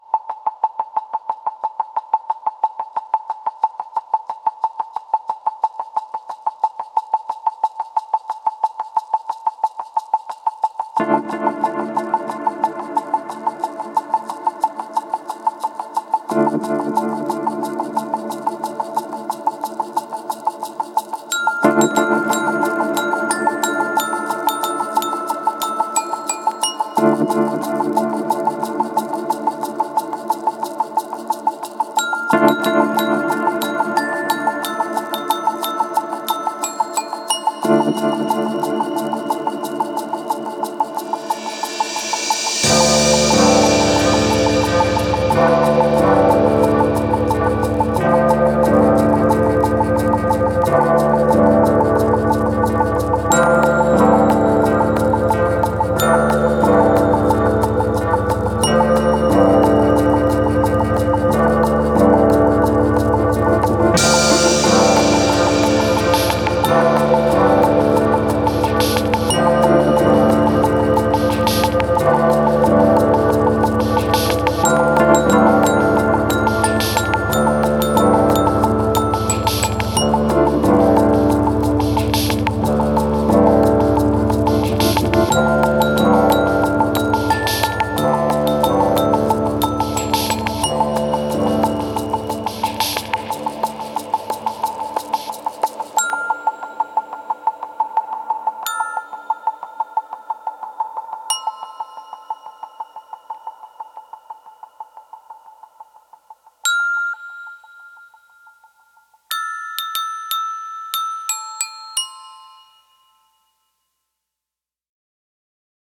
These are dark and mysterious conspiracy sounds.